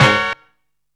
TRAIN HIT.wav